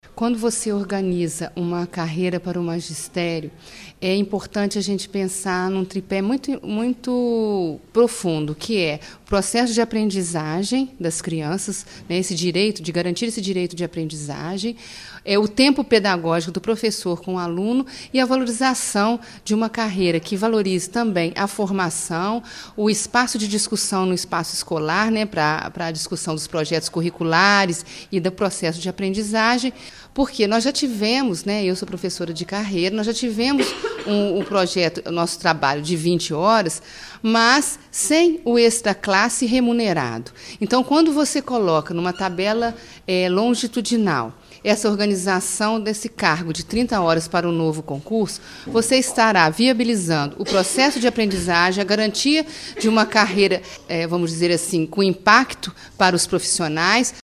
Secretária de Educação, Denise Vieira Franco